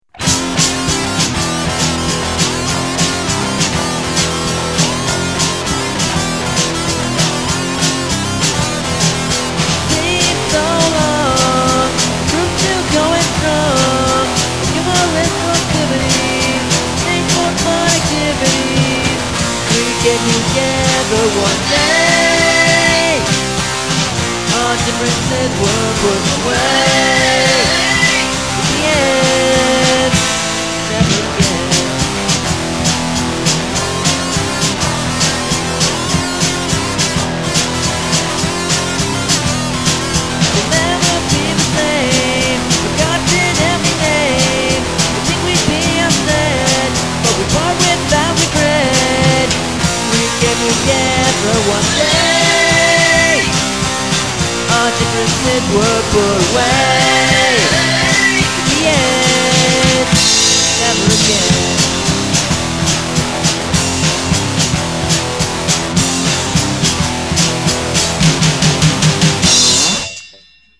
The first full length from a budding pseudo-punk band.
We still only had one mic for the drums.